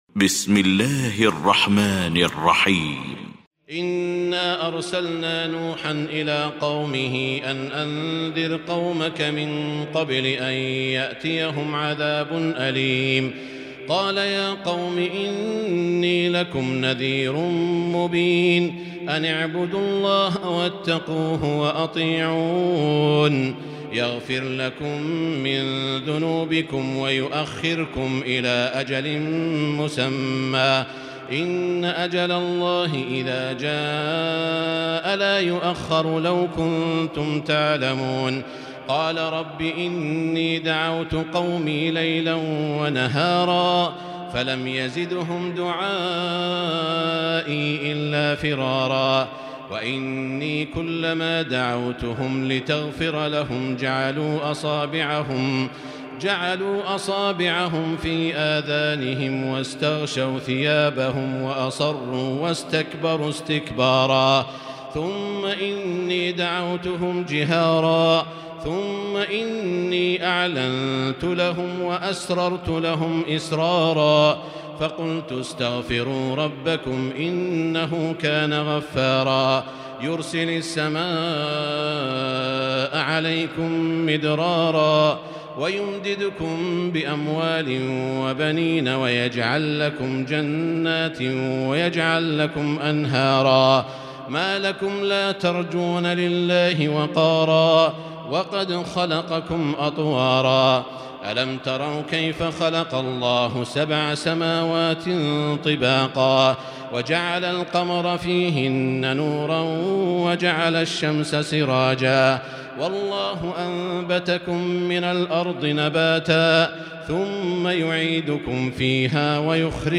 المكان: المسجد الحرام الشيخ: سعود الشريم سعود الشريم نوح